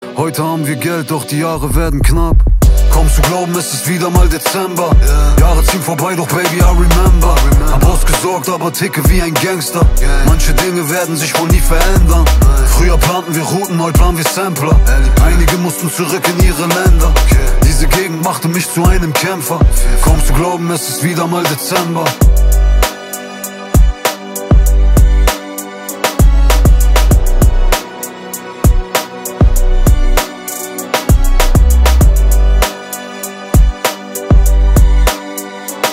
Kategorien: POP